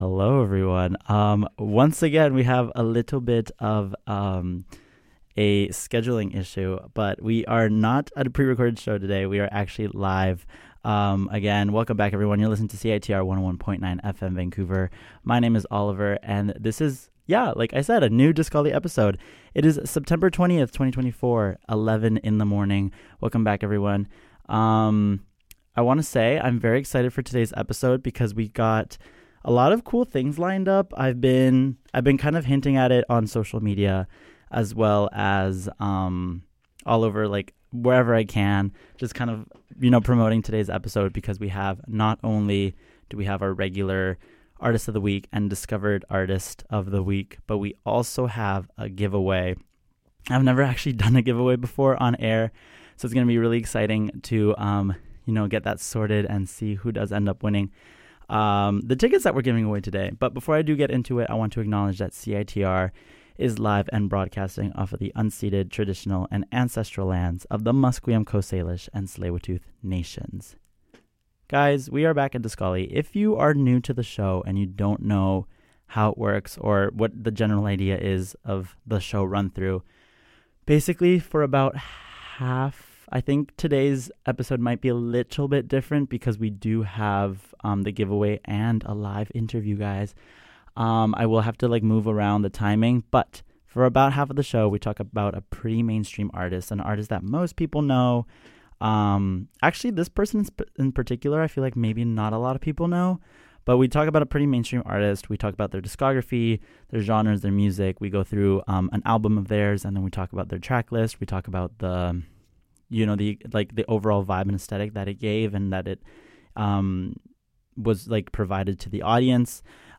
Glitterfox makes a re-appearance on Discollie and joins for a quick interview before ending the show with a ticket giveaway.